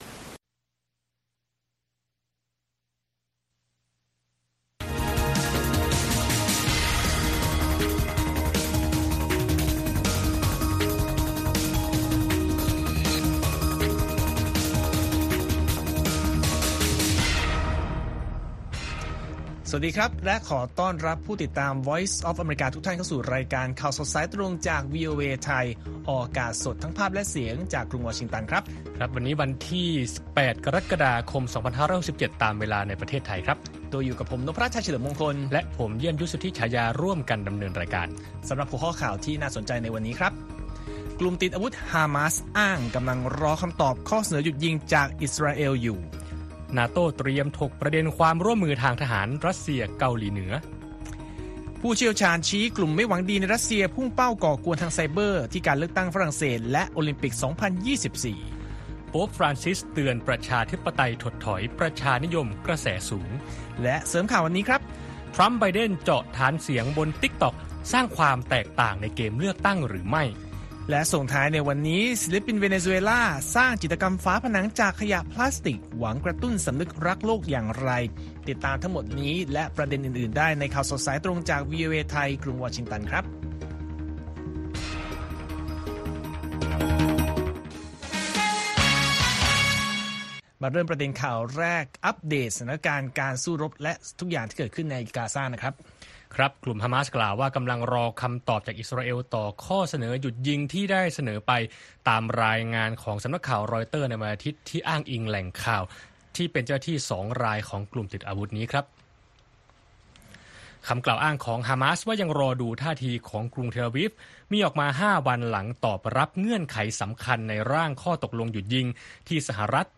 ข่าวสดสายตรงจากวีโอเอ ไทย ประจำวันจันทร์ที่ 8 กรกฎาคม 2567